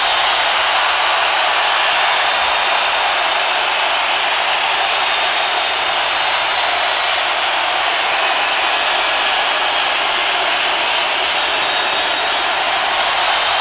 crowd.mp3